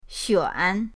xuan3.mp3